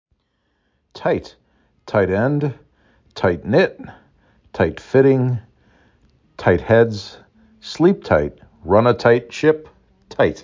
t I t